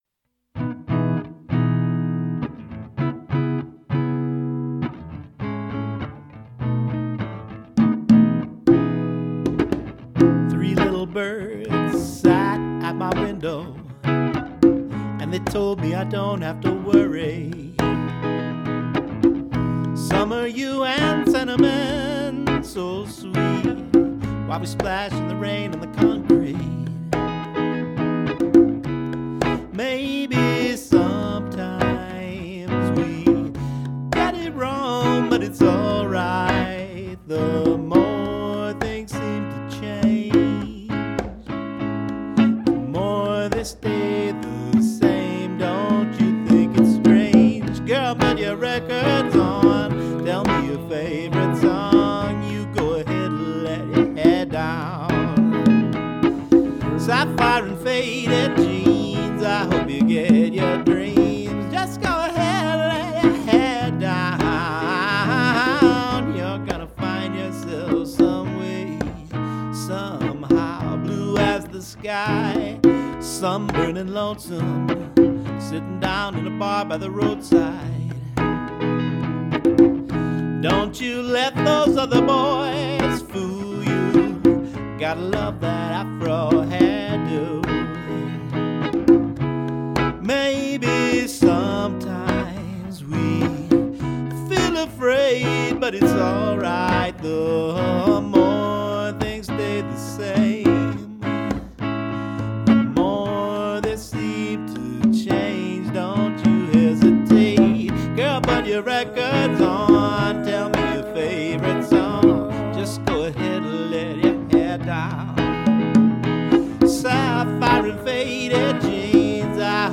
congas with a little harmony
Acoustic Soul with a Latin Groove